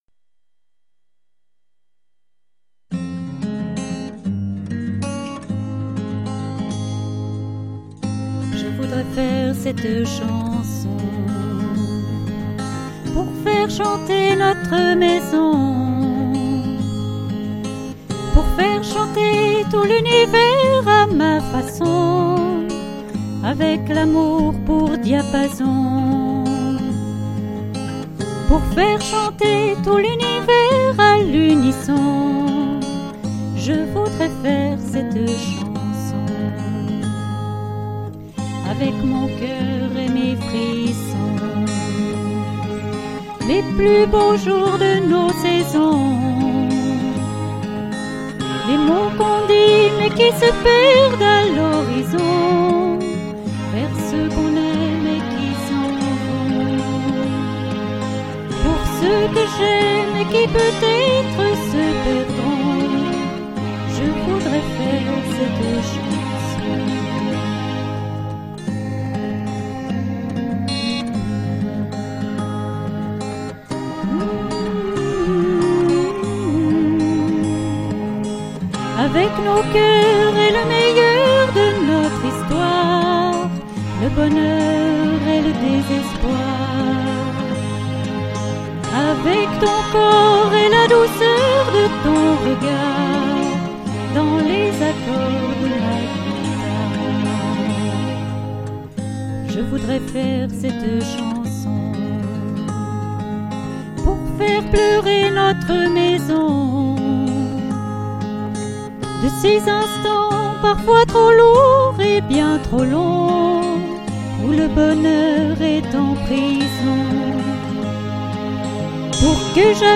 Ecouter une voix chantée,